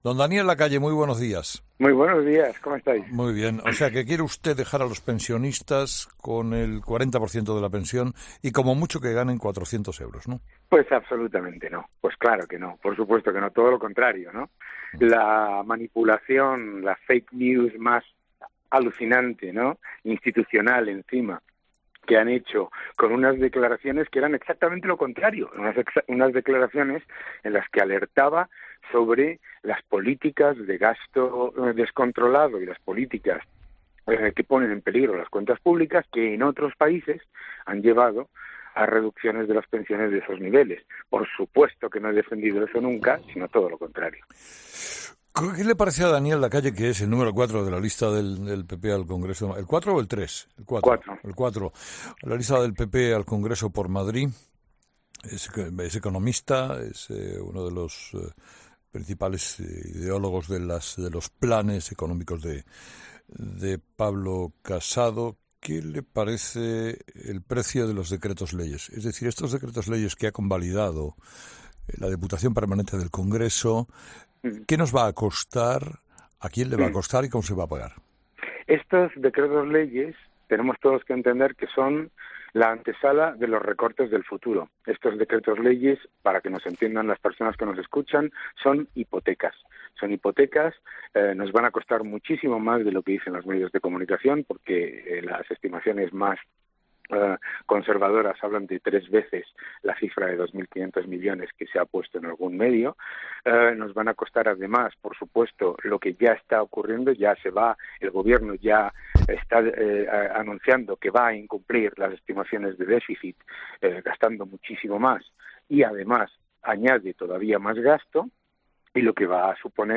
El responsable del programa económico del PP, Daniel Lacalle en 'Herrera en COPE'